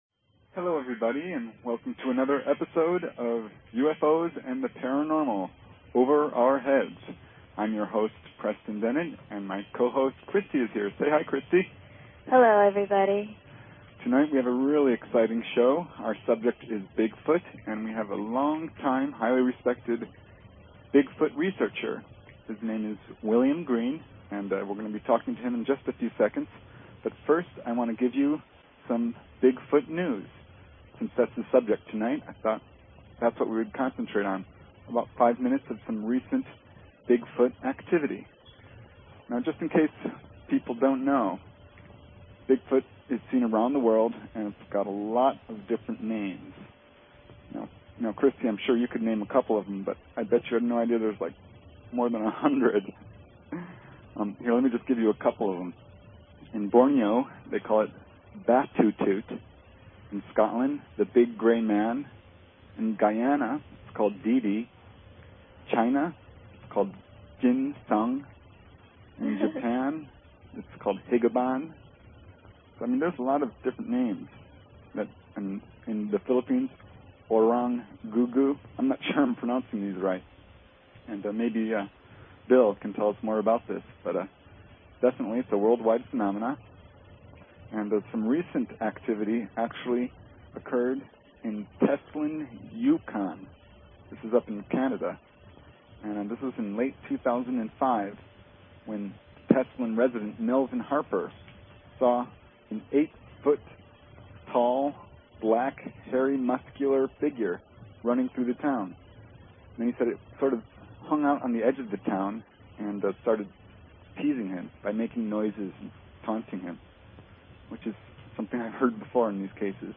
Talk Show Episode, Audio Podcast, UFOs_and_the_Paranormal and Courtesy of BBS Radio on , show guests , about , categorized as